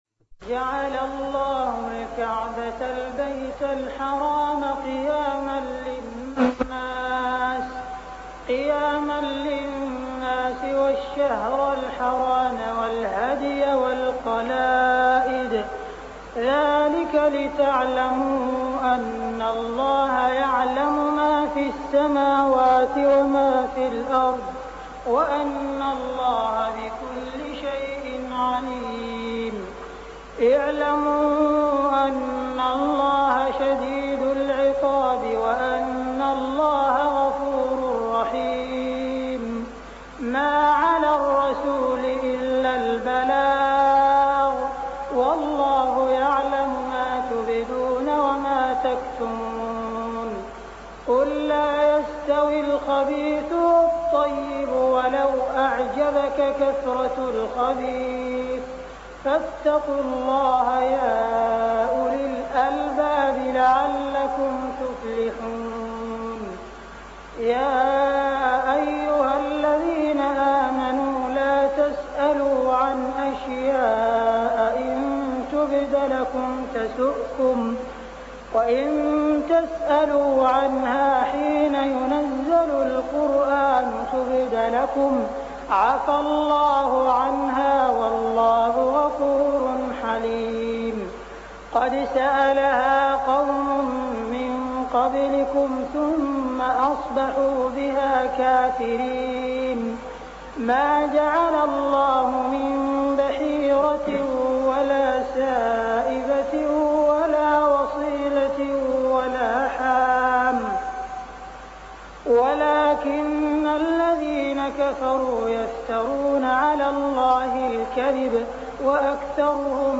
صلاة التراويح ليلة 8-9-1407هـ سورتي المائدة 97-120 و الأنعام 1-58 | Tarawih Prayer Surah Al-Ma'idah and Al-An'am > تراويح الحرم المكي عام 1407 🕋 > التراويح - تلاوات الحرمين